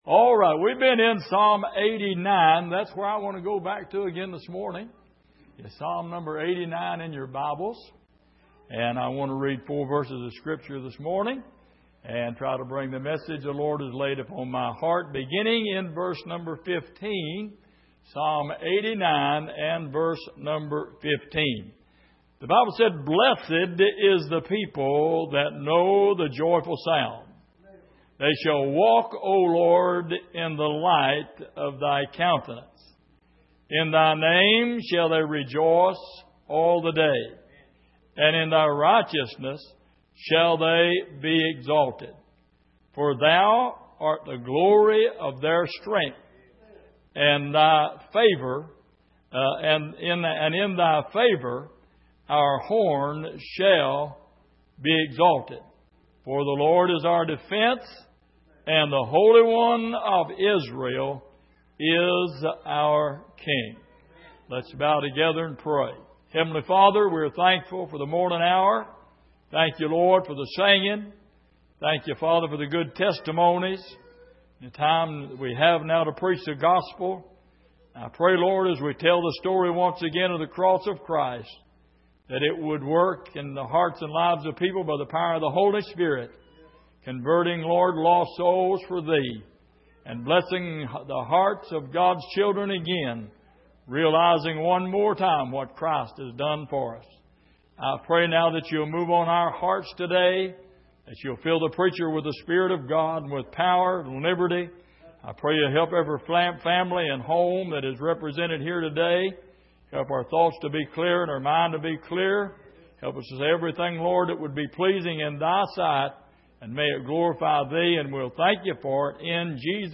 Passage: Psalm 89:15-18 Service: Sunday Morning